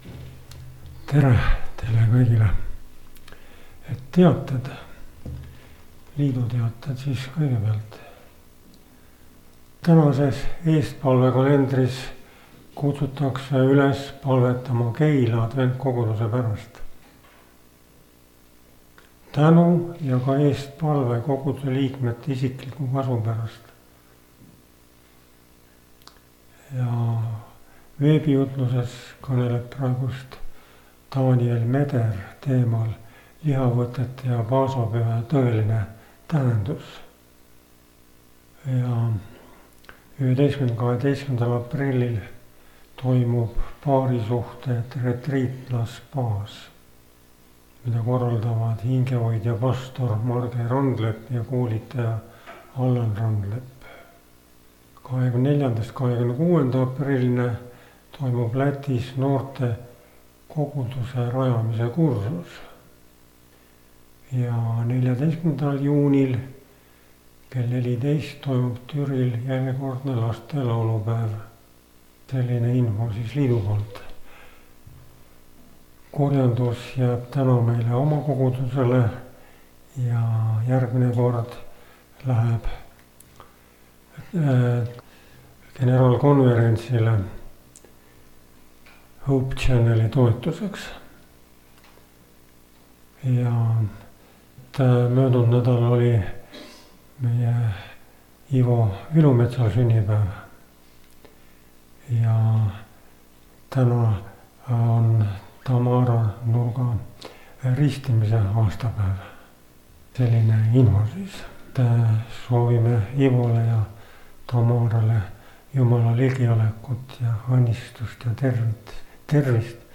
Koosolekute helisalvestused